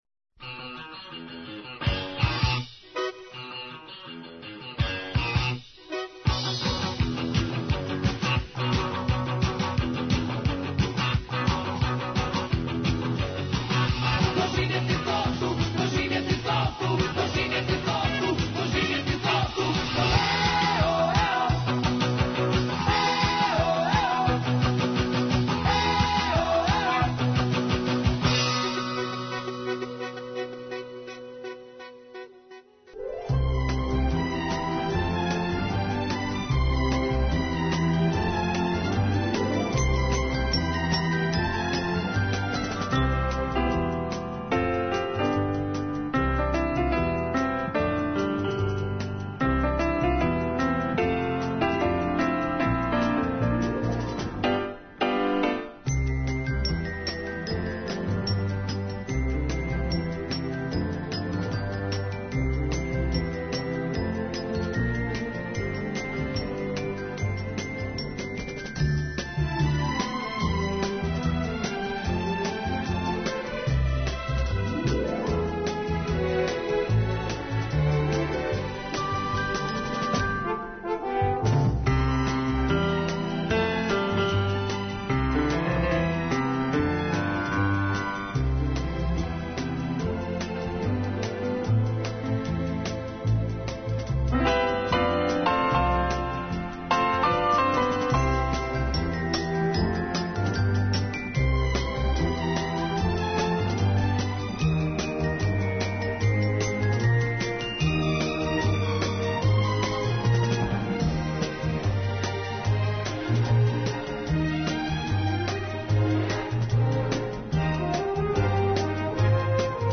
евергрин песмама